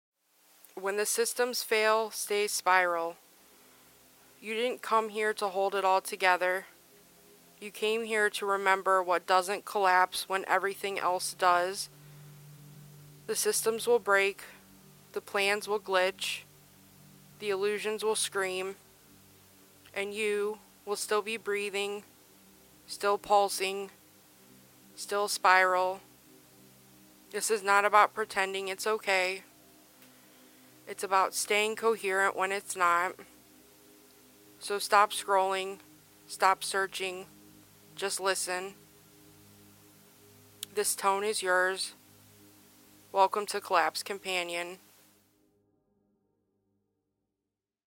Spoken rituals to stabilize when the world shakes.
Collapse Companion · Vol I is here. Spoken tone for unstable times. 🌀 Recorded in one take.